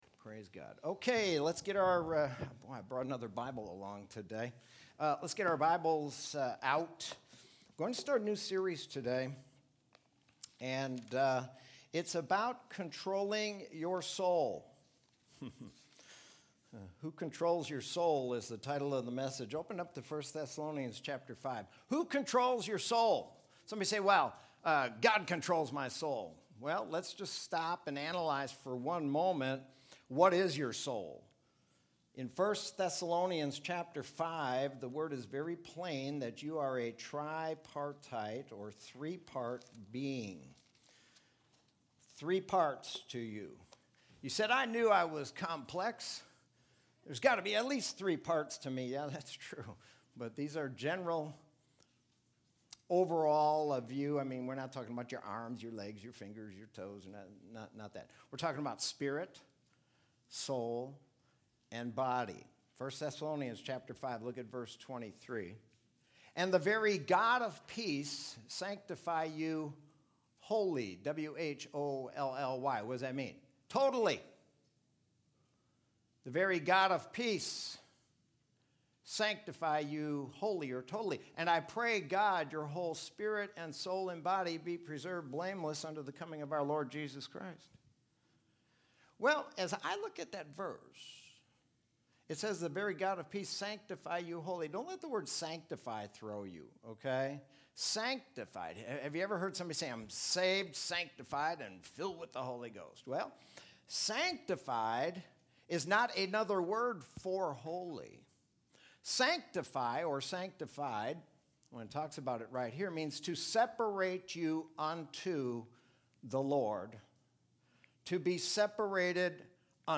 Sermon from Sunday, July 26, 2020.